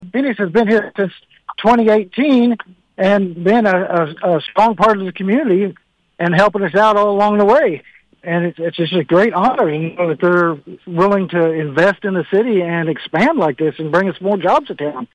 Mayor Donald Clear says the city is excited to see its industries grow.